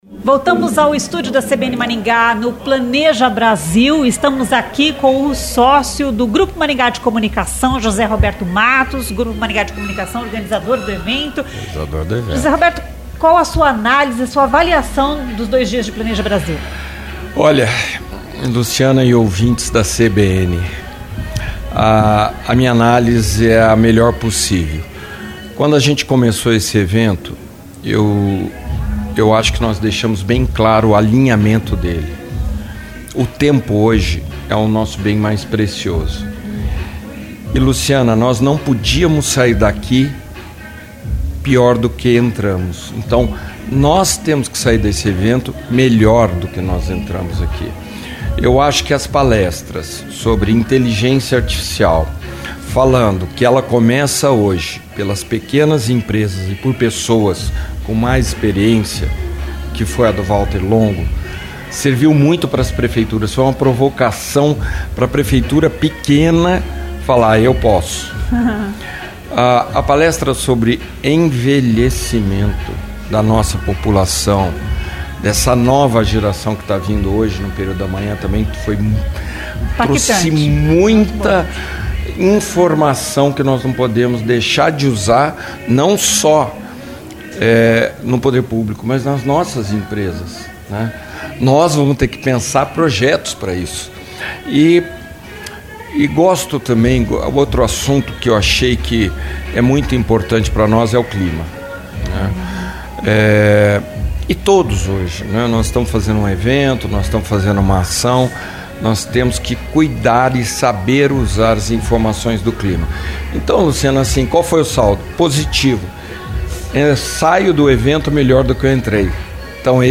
A edição 2025 do Planeja Brasil é realizada nessa terça (21) e quarta (22), no Centro de Eventos Paraná Expo, em Maringá. A entrevista foi realizada no estúdio móvel CBN instalado no local do evento.